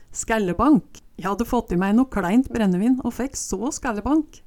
skællebank - Numedalsmål (en-US)
Hør på dette ordet Ordklasse: Substantiv hankjønn Kategori: Kropp, helse, slekt (mennesket) Attende til søk